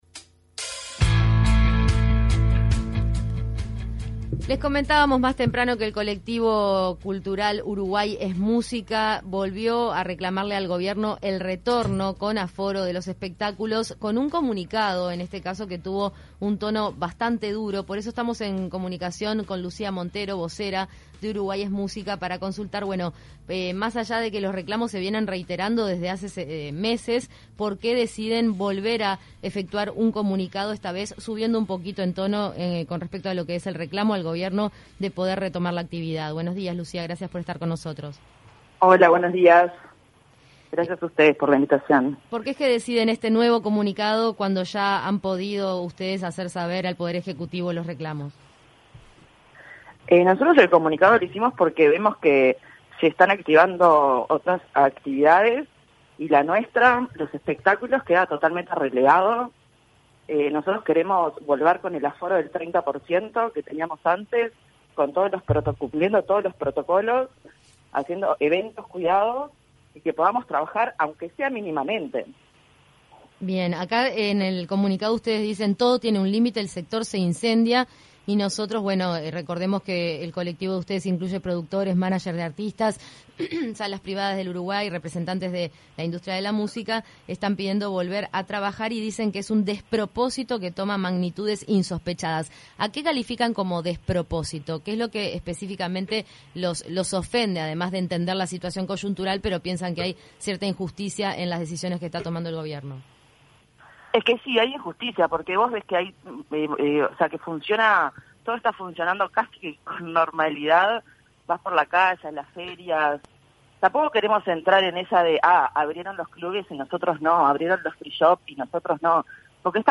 El colectivo Uruguay es Música emitió un comunicado en el que expresan que la resistencia a la apertura de los espectáculos públicos es un «despropósito» que toma «magnitudes insospechadas» dada la «impecabilidad» con la que se aplicaron los protocolos. En entrevista con 970 Noticias Primera Edición